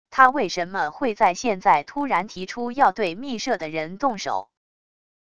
他为什么会在现在突然提出要对秘社的人动手wav音频生成系统WAV Audio Player